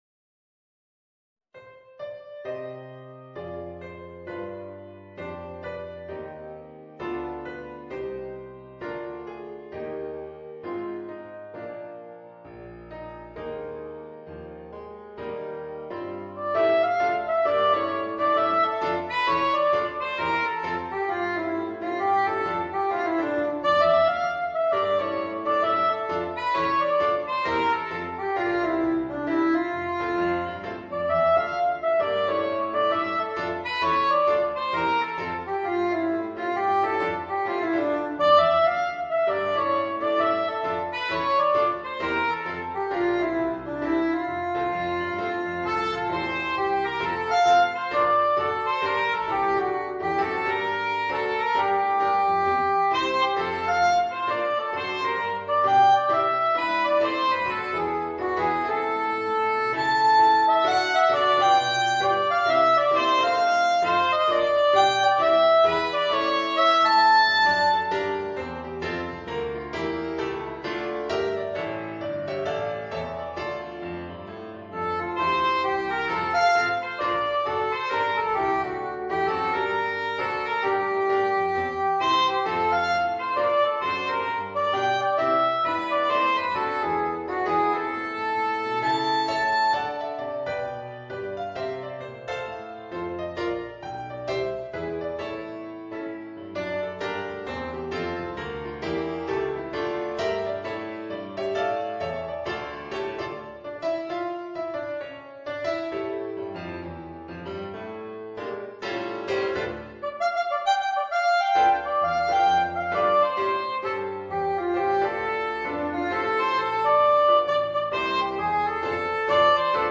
Voicing: Soprano Saxophone and Piano